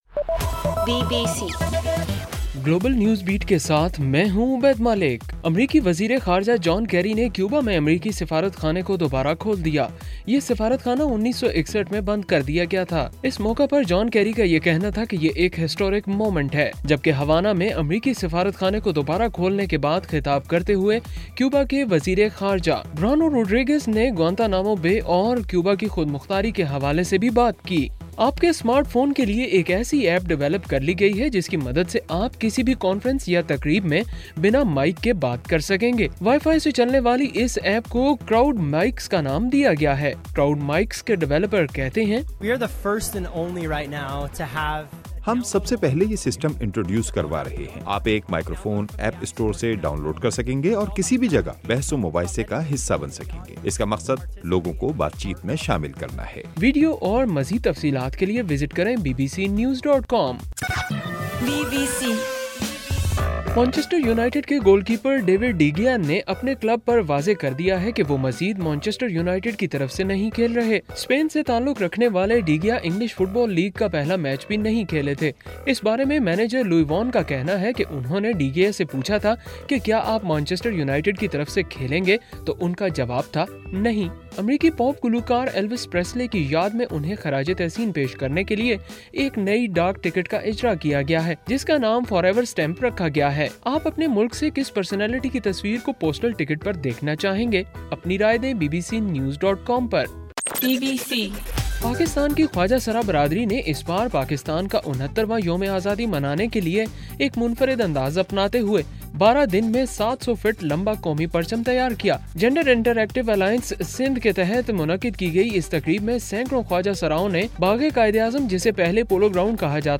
اگست 15: صبح 1 بجے کا گلوبل نیوز بیٹ بُلیٹن